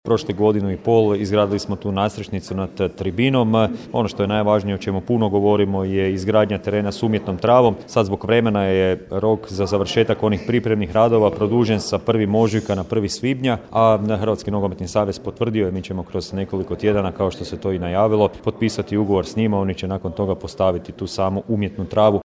NK Mladost Komet, redovna godišnja skupština kluba, 10.2.2023. / Poduzetnički centar Prelog